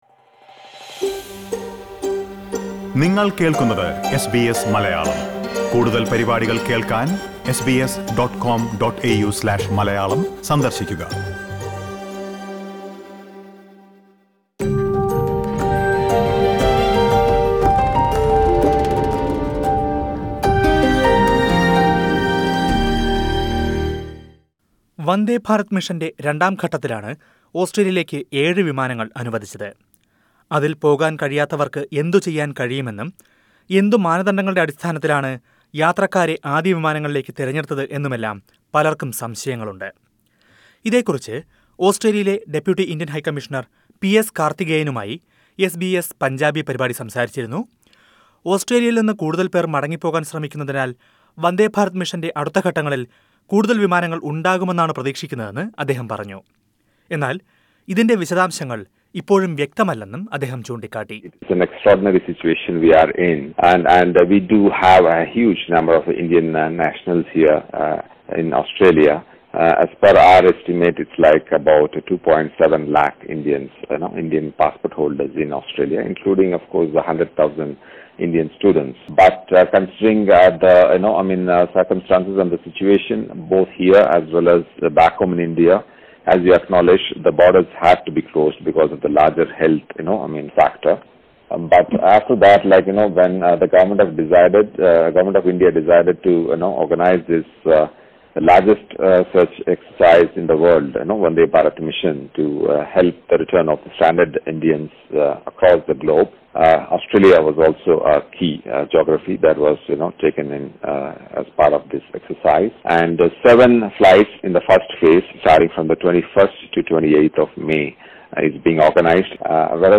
Many Indians stranded in Australia have come forward expressing their dissatisfaction in not getting a chance to go back home in the first phase of Vande Bharat mission. However, India's Deputy High Commissioner to Australia Mr. P S Karthigeyan explained the criteria followed the authorities in an exclusive interview to SBS Punjabi.